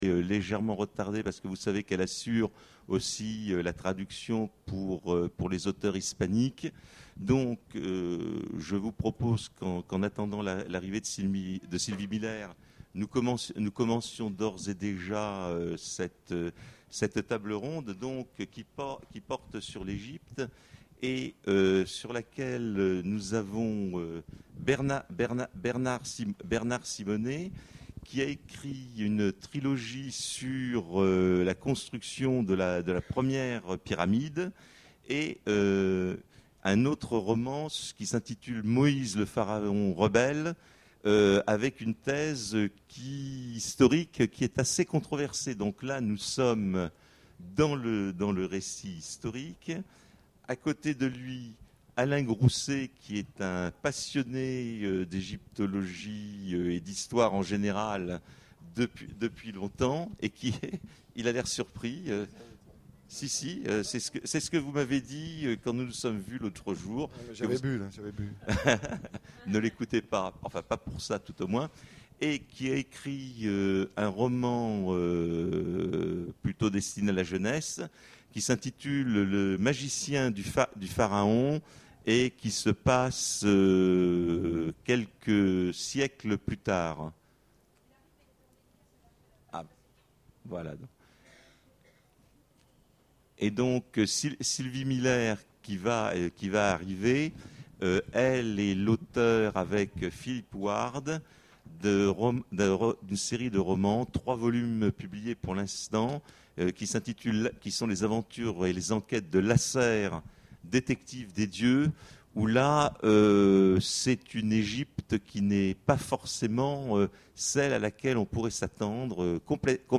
Imaginales 2014 : Conférence Des récits en tous genres !